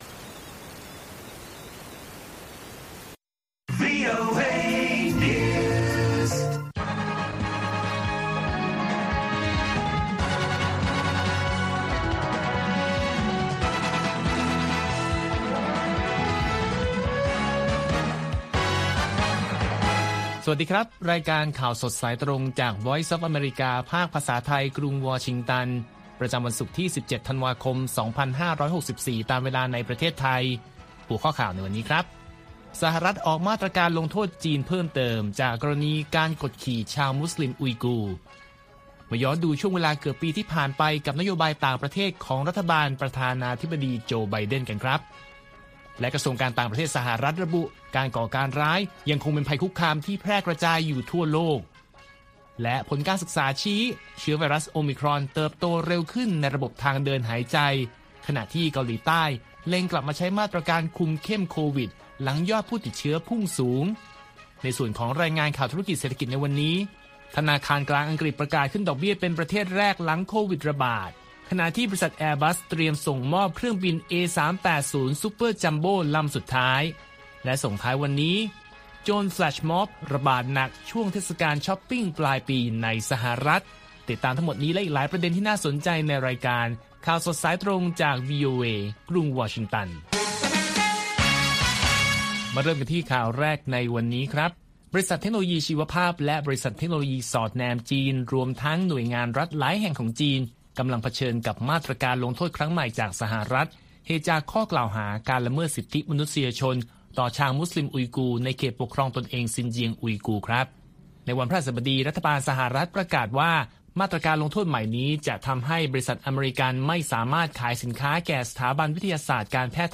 ข่าวสดสายตรงจากวีโอเอ ภาคภาษาไทย ประจำวันศุกร์ที่ 17 ธันวาคม 2564 ตามเวลาประเทศไทย